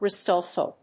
音節re・sult・ful 発音記号・読み方
/rɪzˈʌltf(ə)l(米国英語)/